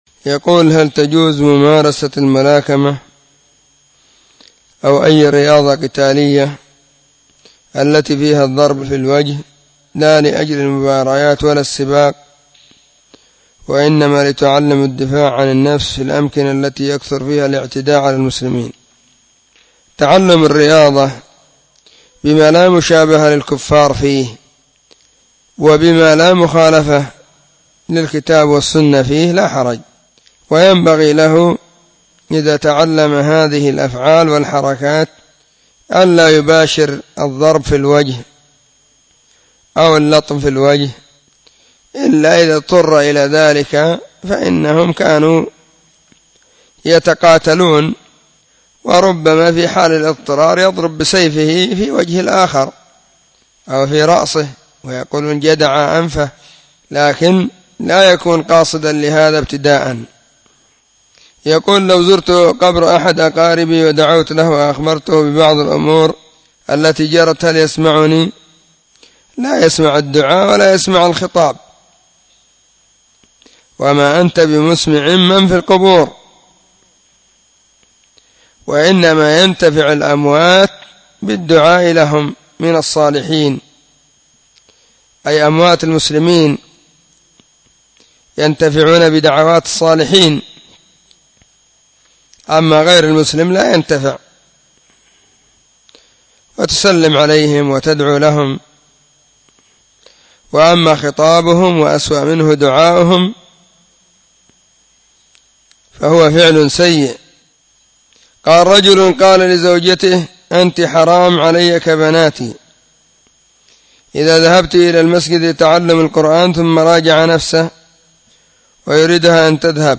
🔹 سلسلة الفتاوى الصوتية 🔸